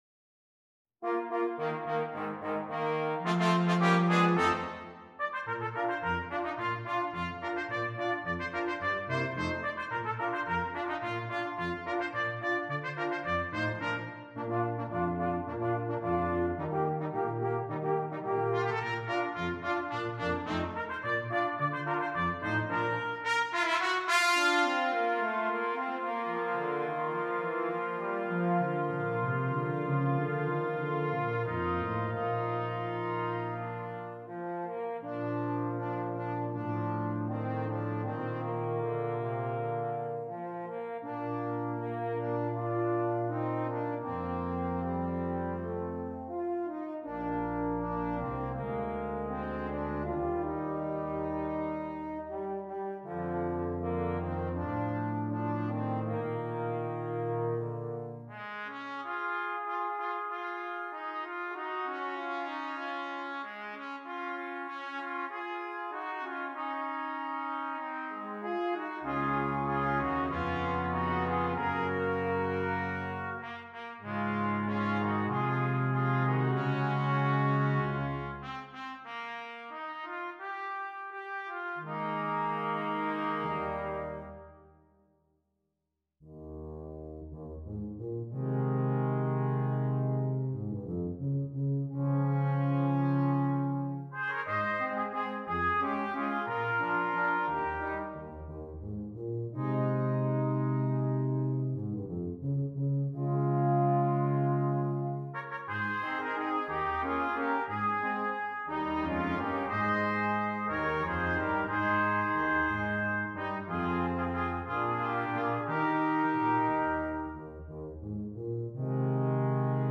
Brass Quintet
Traditional